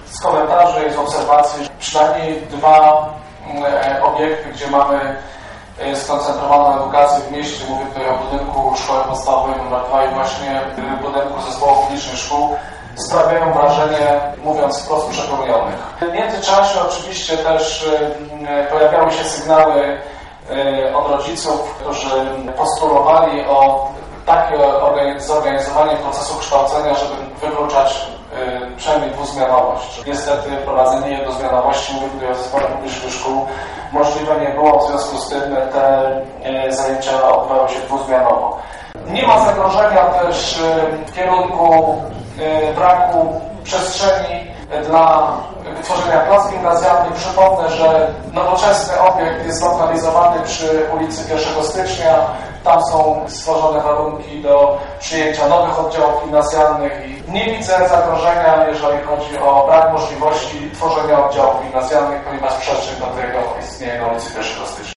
Taką wolę wyrazili podczas sesji Rady Miasta 10 lutego żnińscy radni.
Argumenty jeszcze przed podjęciem uchwały w tej sprawie przedstawił Burmistrz Luchowski.